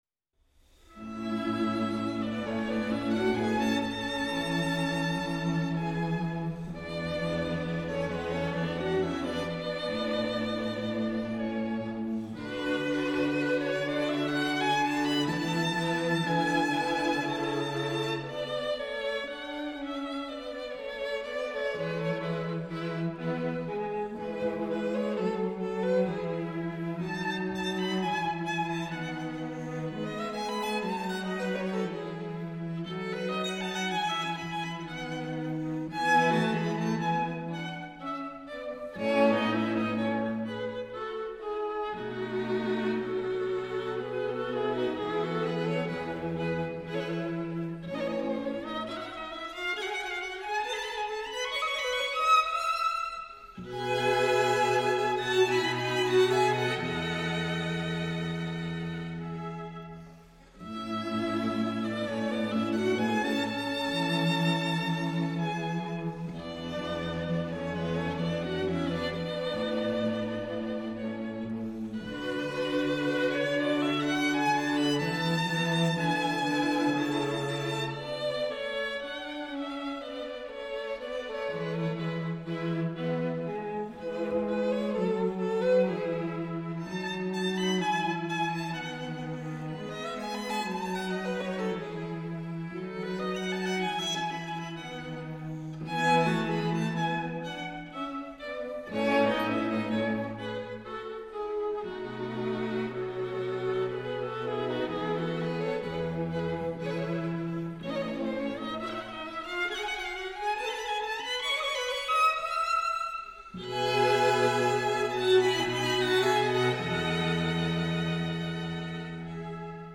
String Quartet in D major
Andante